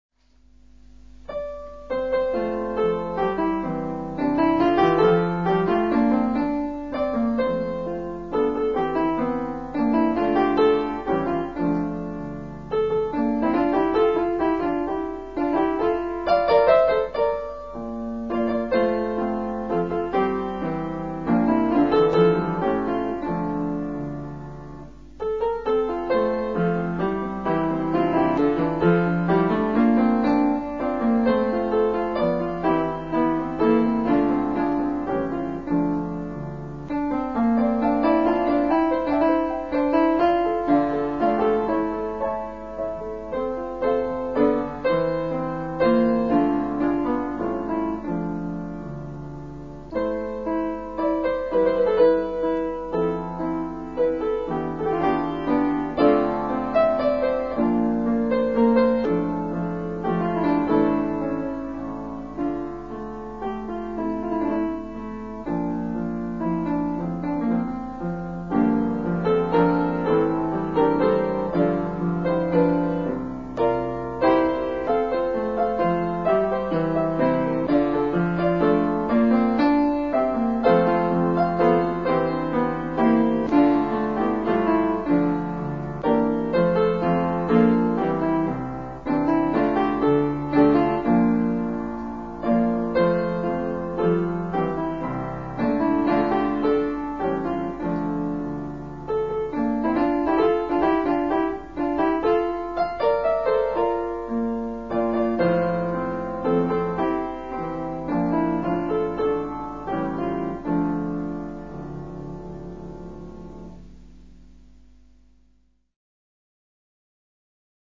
Piano Recital 2:
English Folktune Preludes for Piano, plus Burrows and Benda
all played on the piano